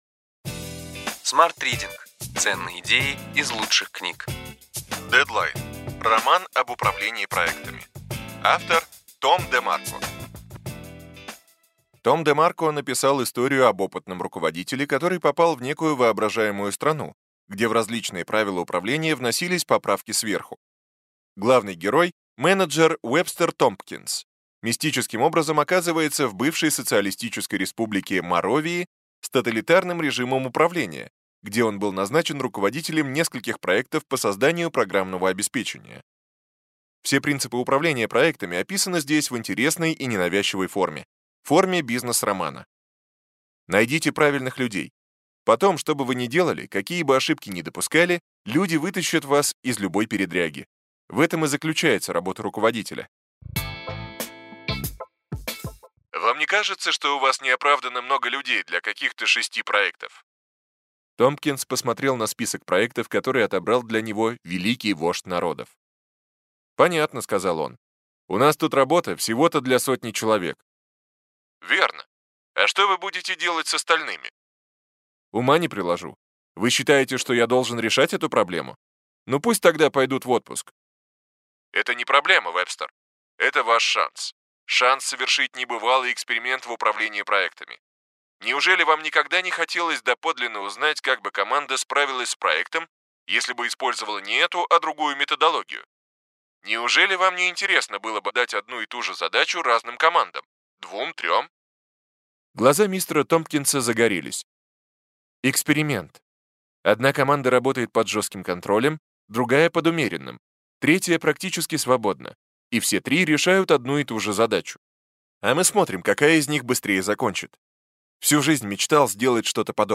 Аудиокнига Ключевые идеи книги: Deadline. Роман об управлении проектами. Том ДеМарко | Библиотека аудиокниг